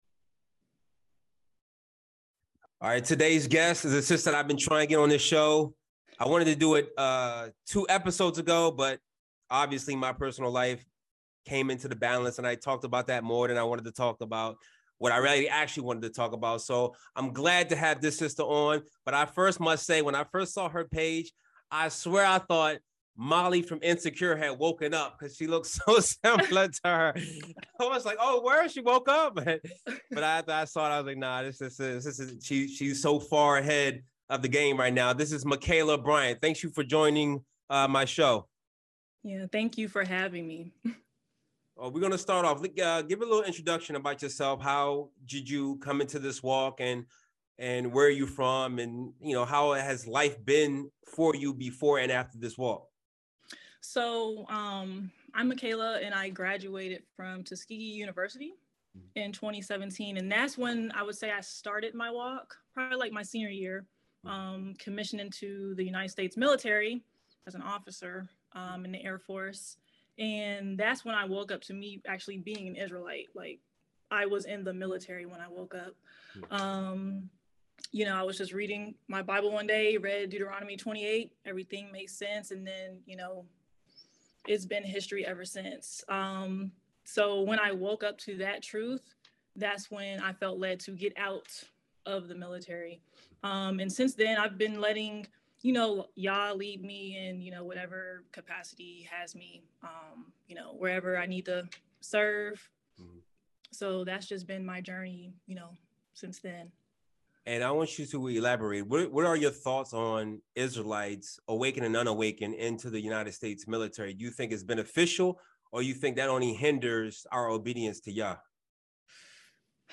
Guest Interview